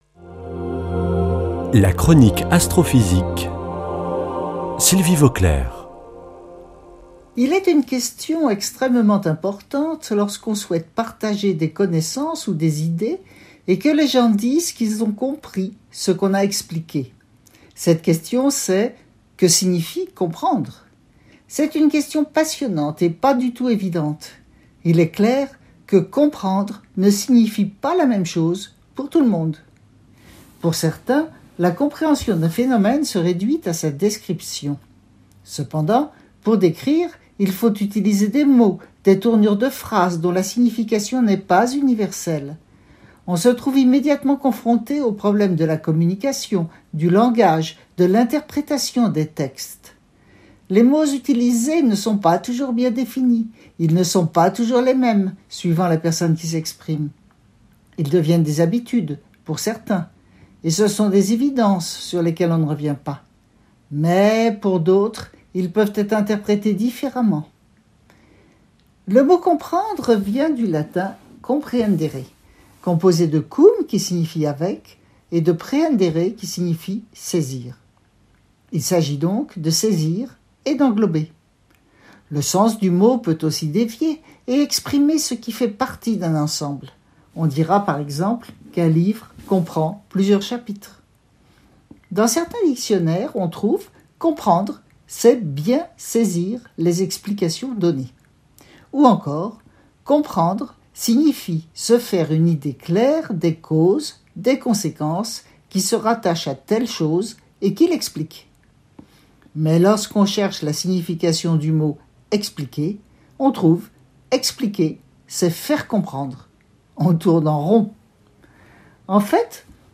mardi 3 août 2021 Chronique Astrophysique Durée 3 min
[ Rediffusion ] Comprendre ne signifie pas la même chose pour tout le monde
Astrophysicienne